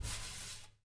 LQB SWISHD-L.wav